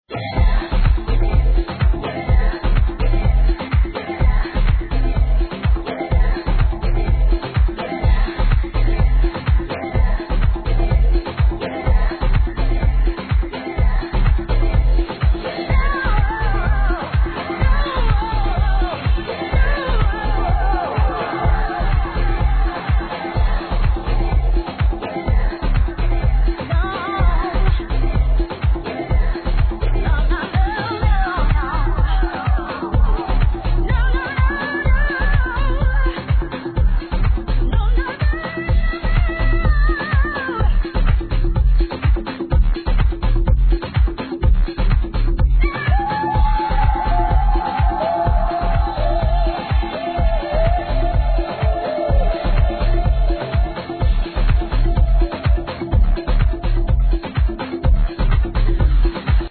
Love Housey ID - Vocals - "Give It Up Give It In"?